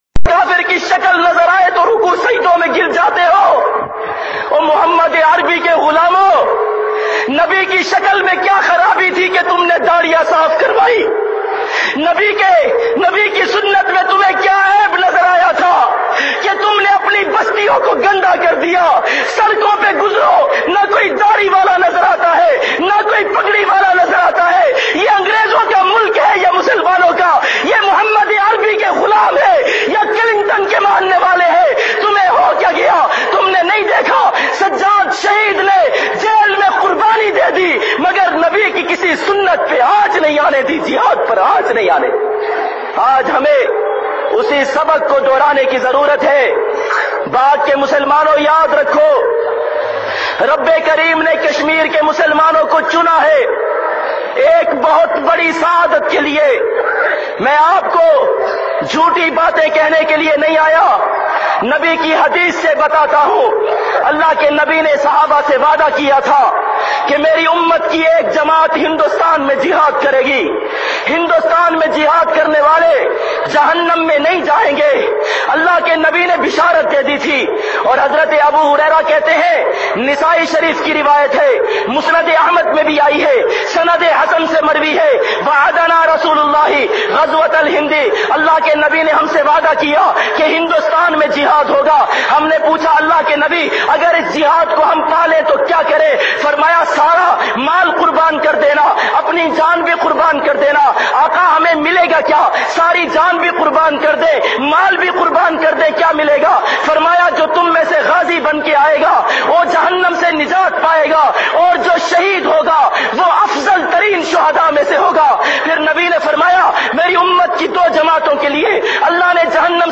JIhad E Kashmir BY Molana Masood Azhar bayan mp3